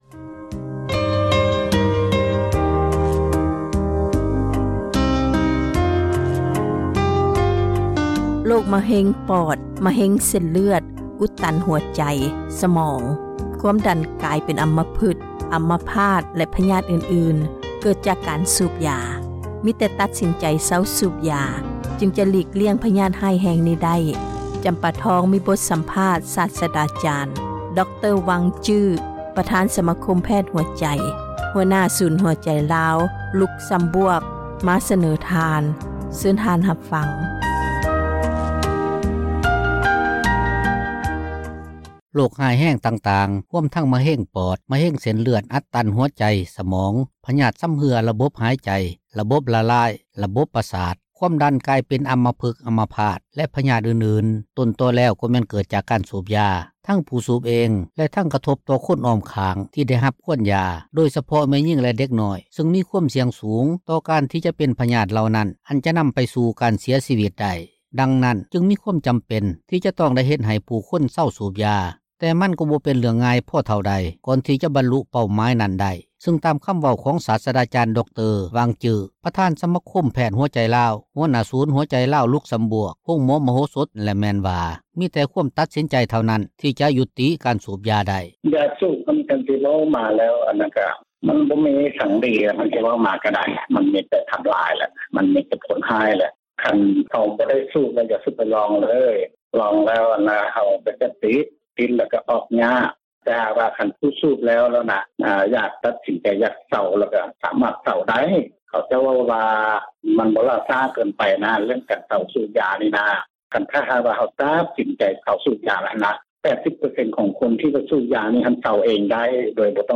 ຜ່ານທາງ ໂທຣະສັບ